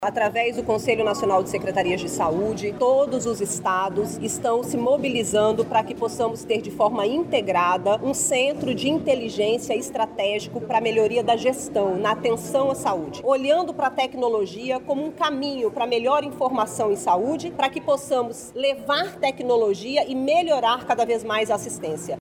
A secretária de Saúde do Amazonas, Nayara Maksoud, explica que os Estados se preparam para a criação de um Centro de Inteligência Estratégica.